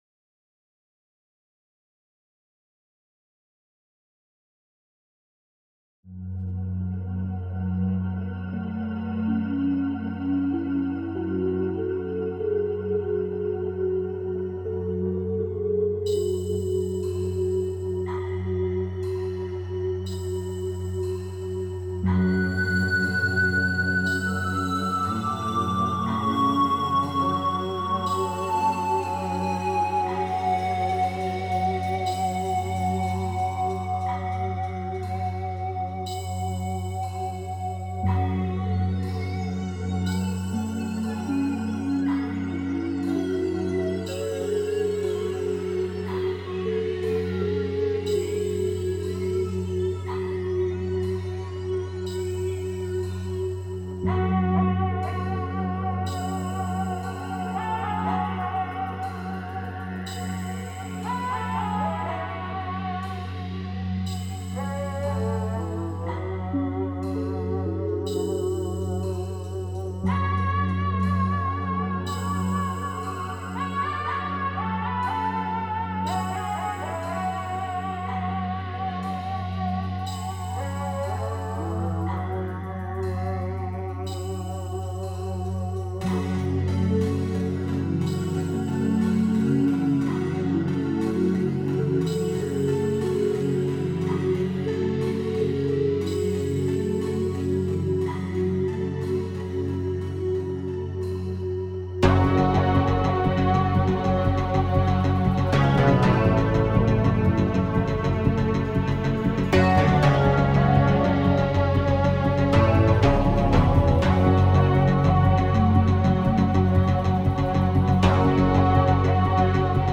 Христианская музыка